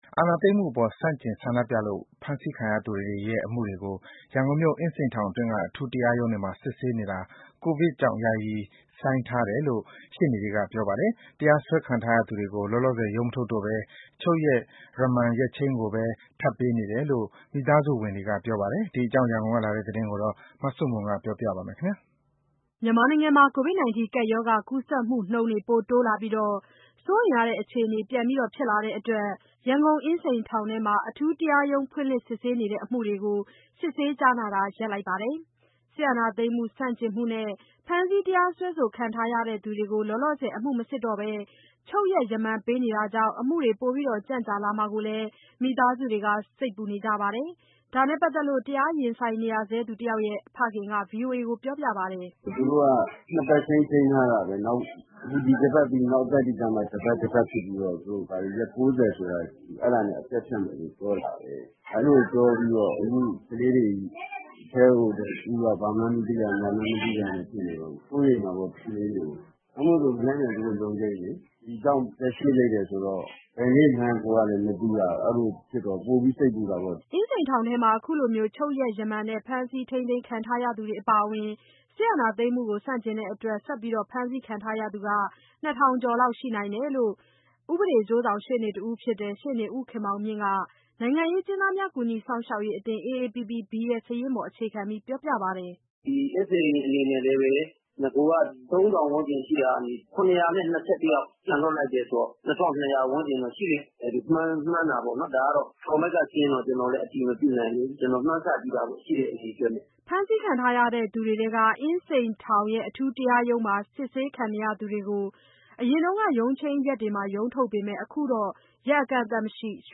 ကိုဗဈကပျရောဂါကူးစကျမှုနှုနျး စိုးရိမျရတဲ့အခွအေနေ ပွနျဖွဈလာတဲ့အတှကျ ရနျကုနျအငျးစိနျထောငျထဲမှာ အထူးတရားရုံးဖှင့ျလှဈ စဈဆေးနတေဲ့ အမှုတှကေို စဈဆေးကွားနာတာကို ရပျထားပါတယျ။ စဈအာဏာသိမျးမှု ဆန့ျကငြျတဲ့အတှကျ ဖမျးဆီးတရားစှဲခံနရေသူတှကေို လောလောဆယျ အမှုမစဈဘဲ ခြုပျရကျ ရမနျပေးနတောကွောင့ျ အမှုတှေ ပိုကွန့ျကွာမှာကို မိသားစုတှစေိတျပူနကွေပါတယျ။ ဒါ နဲ့ပါတျသကျလို့ တရားရငျဆိုငျနရေသူတယောကျရဲ့ ဖခငျက ဗှီအိုအကေို ခုလိုပွောပါတယျ။